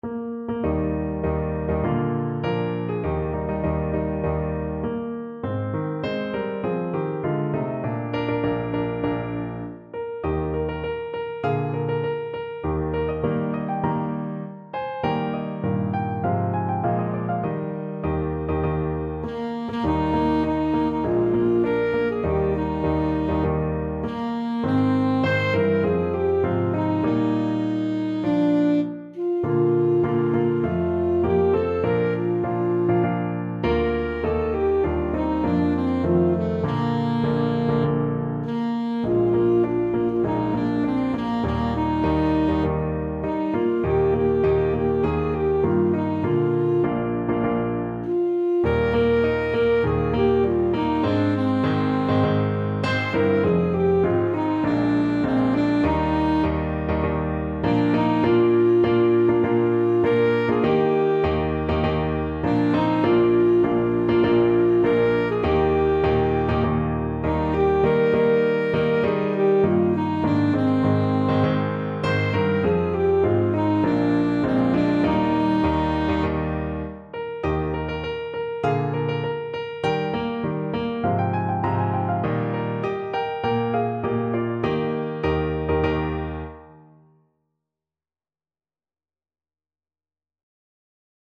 Alto Saxophone
"Columbia, the Gem of the Ocean" is a United States patriotic song which was popular during the mid-19th and early 20th centuries, especially during the Civil War era.
Eb major (Sounding Pitch) C major (Alto Saxophone in Eb) (View more Eb major Music for Saxophone )
Maestoso
4/4 (View more 4/4 Music)